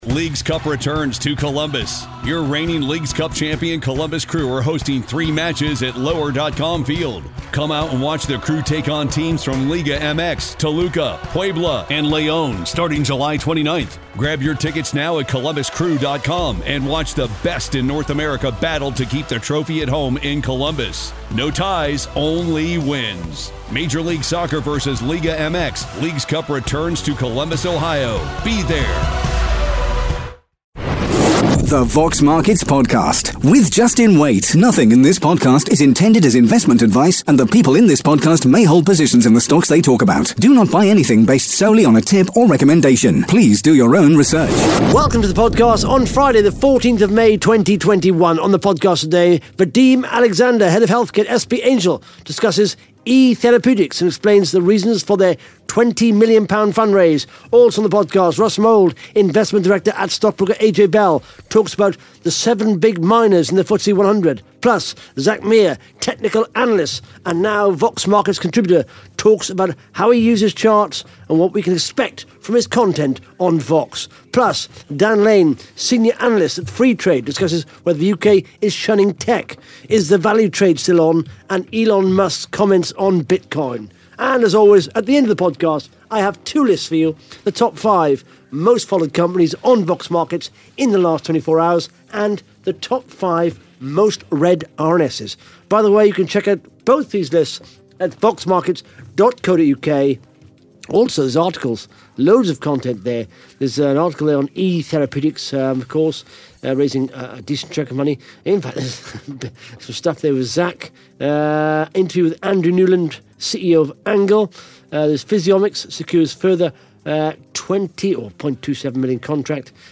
(Interview starts 10 minutes 20 seconds)